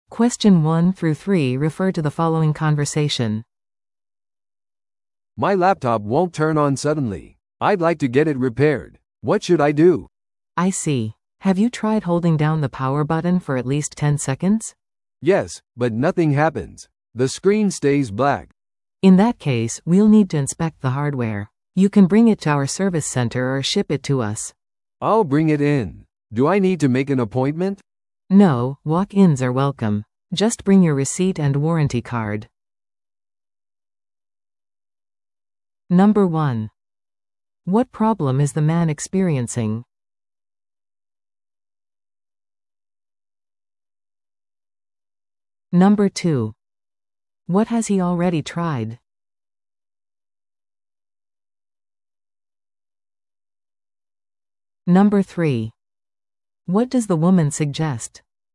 No.1. What problem is the man experiencing?
No.3. What does the woman suggest?